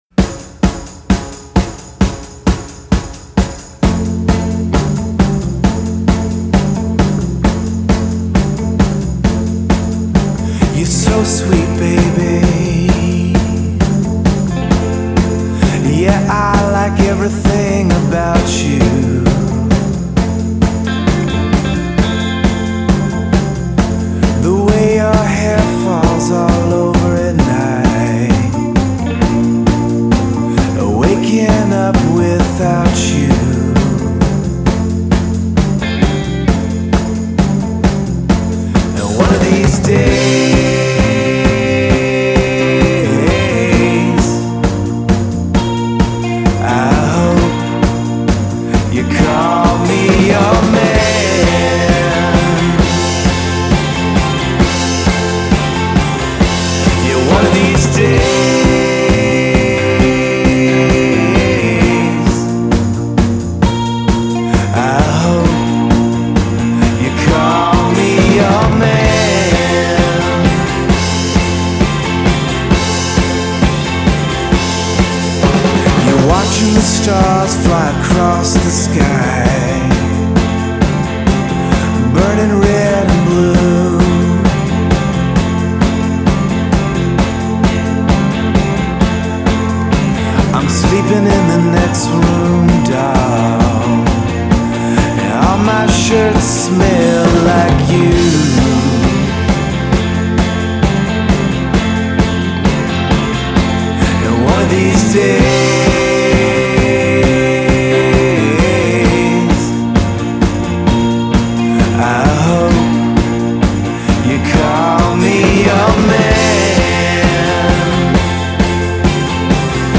Memphis blue-eyed soul sound
has a fine, rough, yet ultimately nondistinct voice